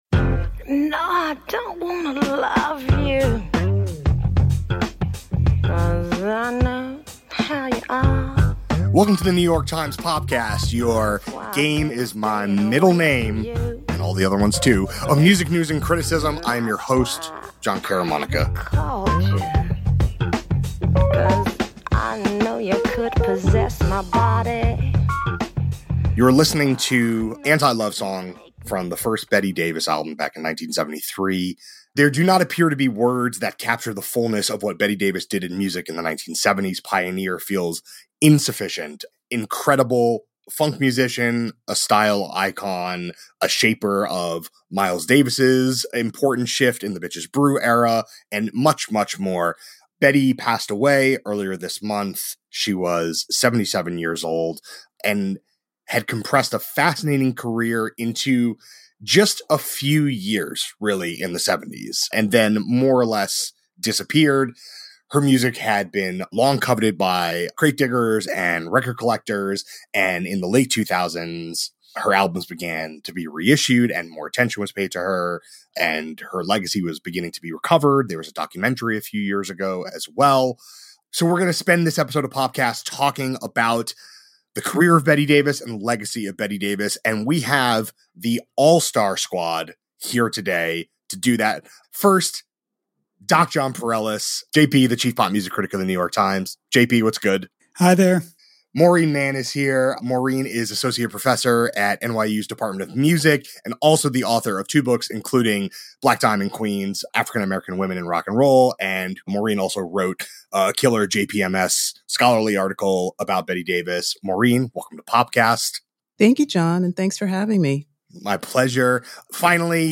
A conversation about her unique music, her short career and the path that led to her rediscovery.